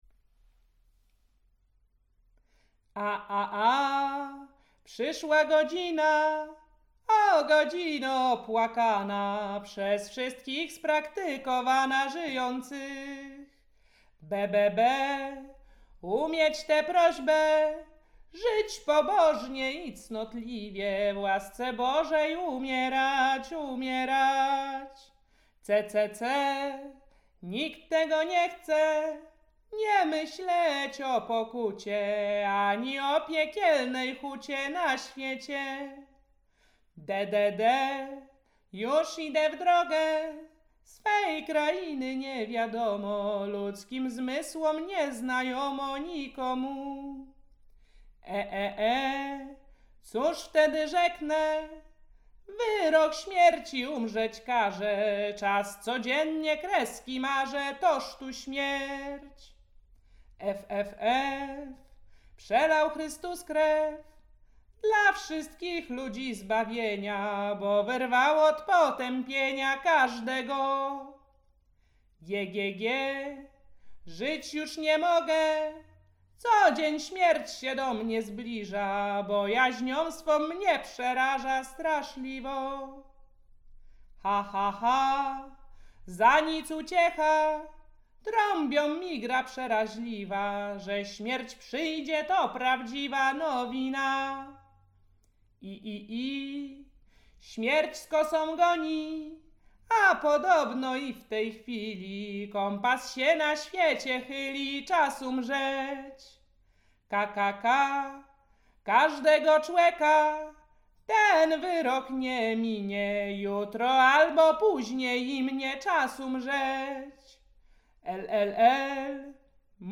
Pogrzebowa
pogrzebowe nabożne katolickie do grobu